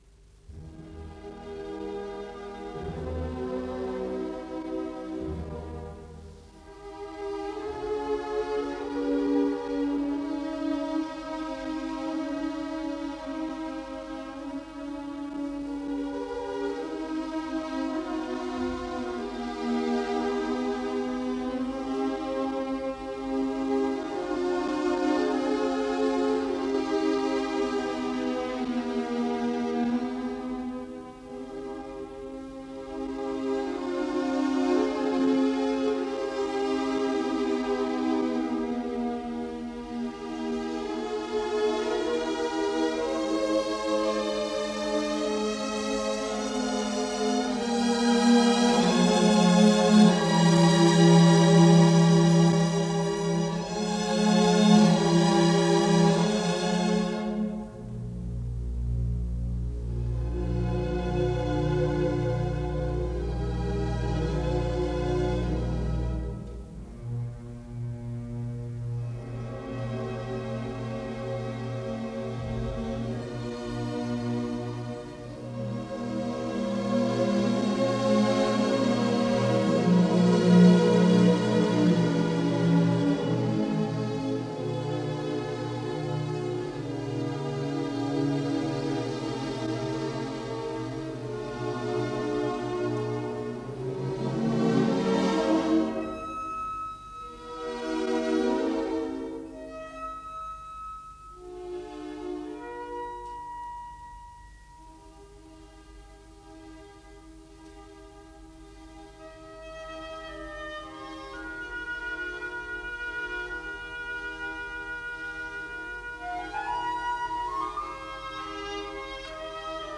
Исполнитель: Государственный симфонический оркестр СССР
Соль минор.